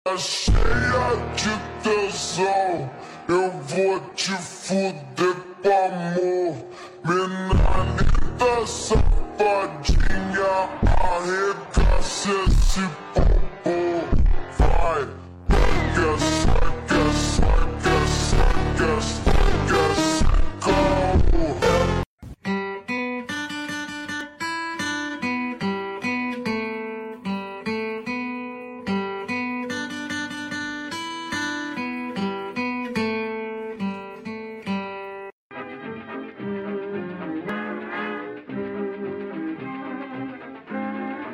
Primal Egg Hatching Paldo sa sound effects free download